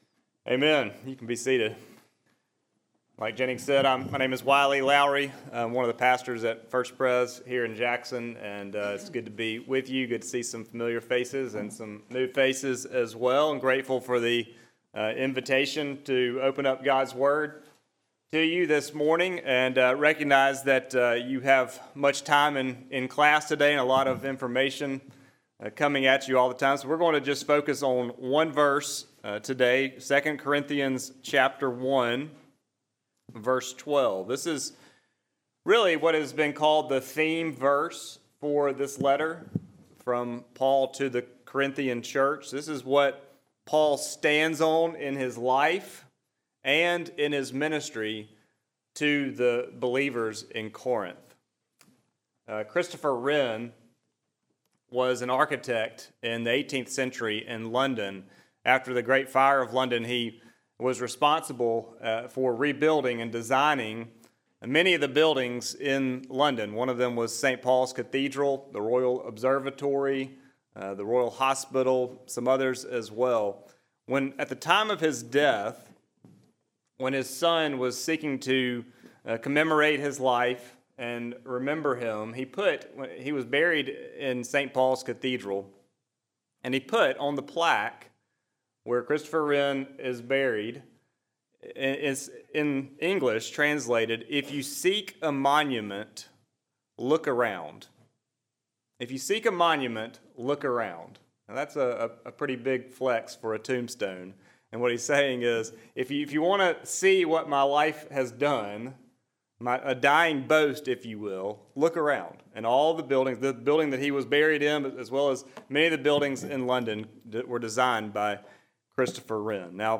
Chapel at RTS Jackson